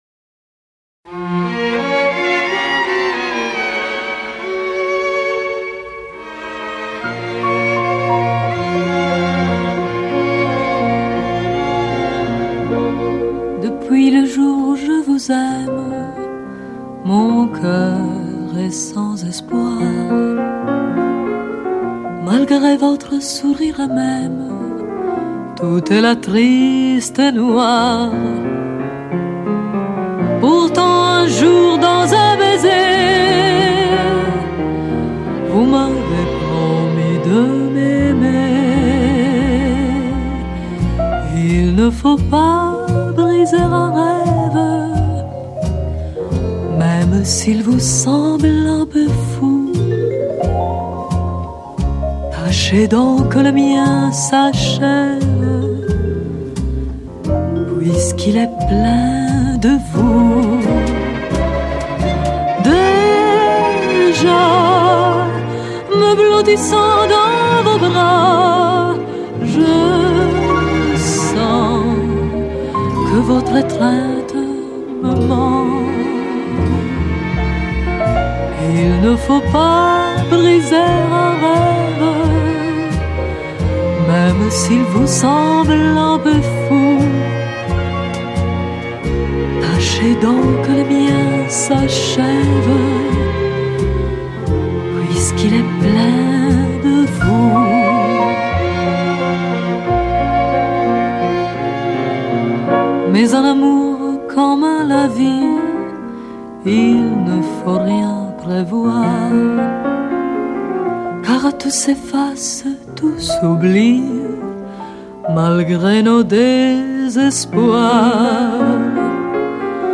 Una vieja y hermosa canción francesa